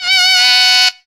HI WAILER.wav